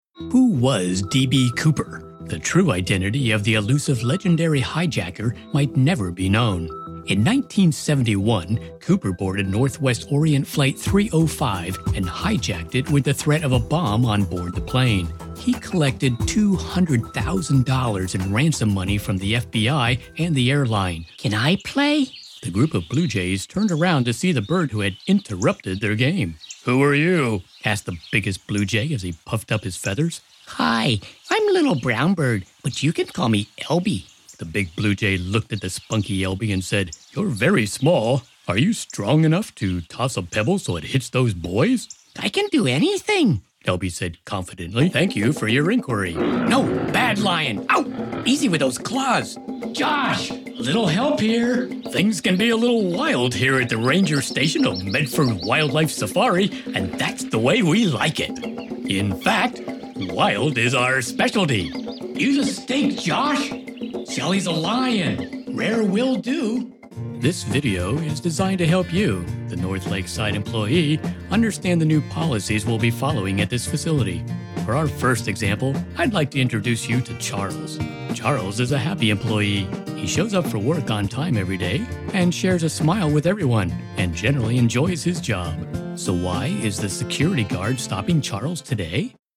Narrative Reads
NARRATIVE DEMO.mp3